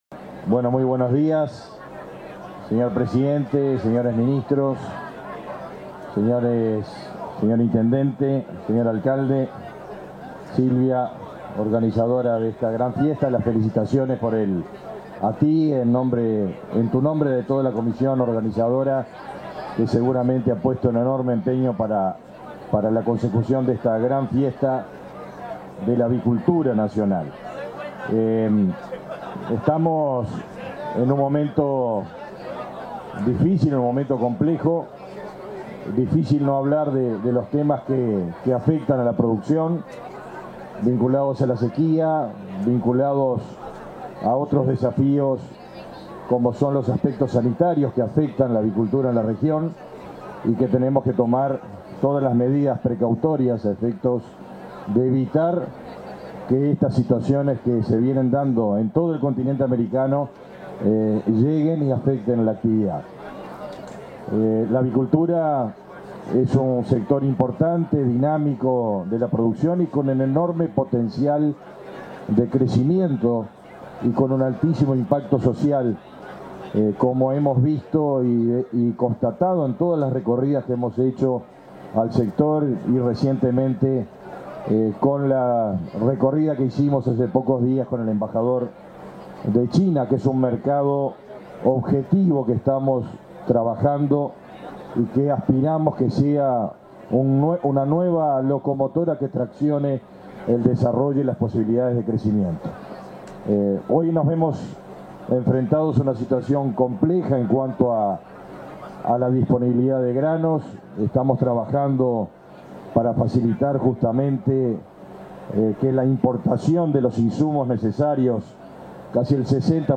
Palabras del ministro de Ganadería, Agricultura y Pesca, Fernando Mattos
En el marco de la 11.ª edición de la Expo Avícola: Fiesta del Pollo y la Gallina 2023 en San Bautista, se expresó el ministro de Ganadería,